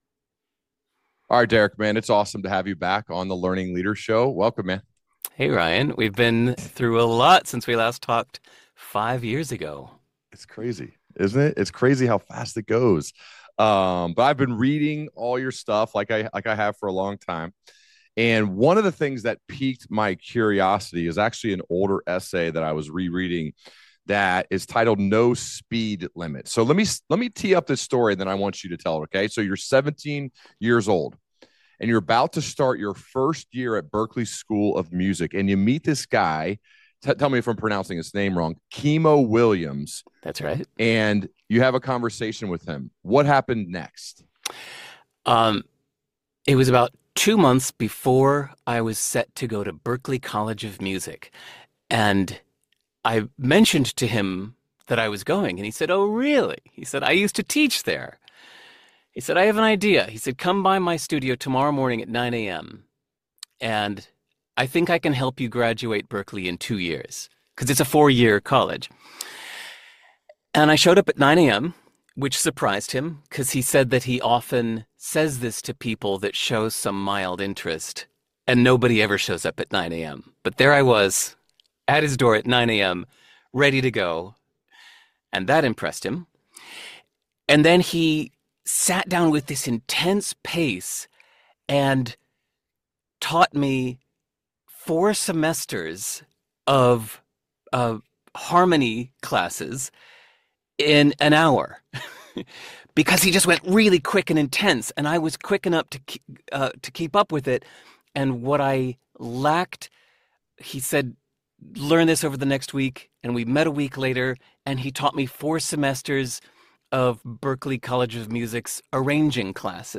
Interviews: